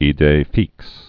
(ē-dā fēks)